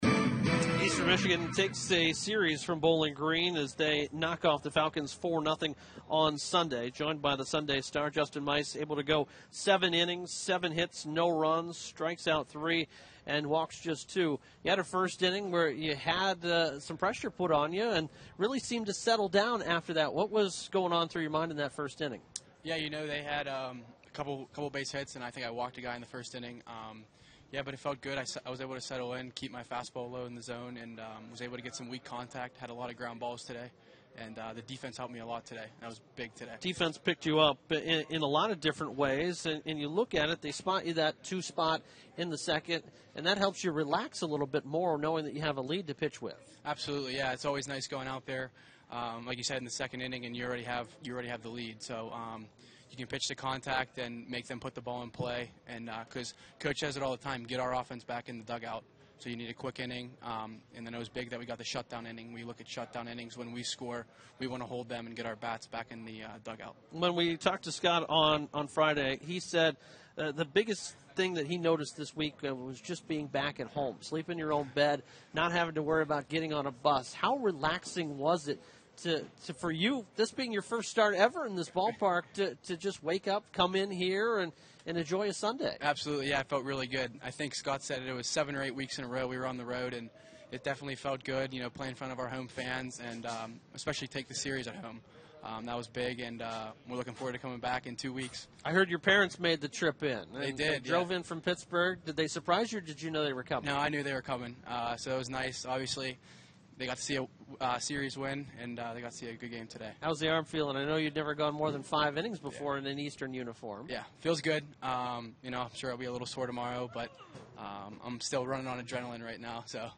Postgame Audio: